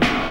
0203 DR.LOOP.wav